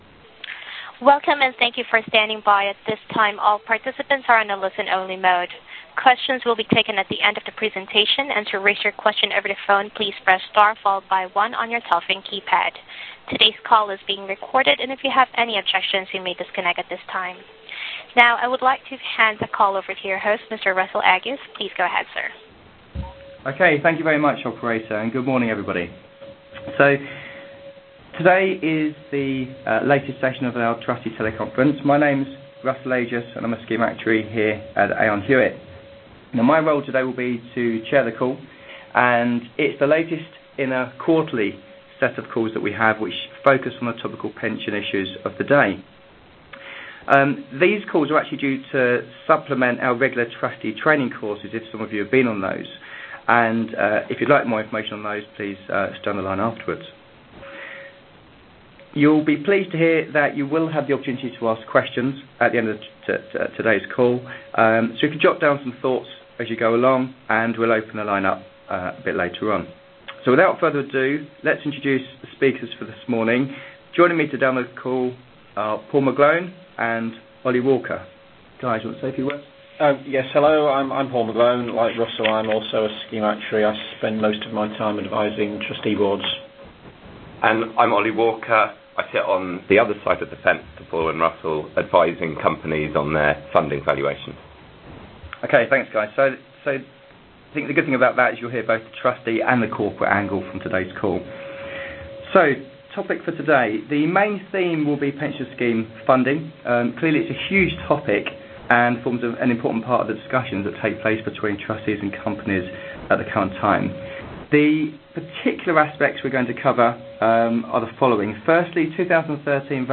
Recorded Teleconference: Topical Trustee Issues | United Kingdom
At this teleconference the panel will be discussing some of the key features, themes and initial views on 2013 funding valuations, including: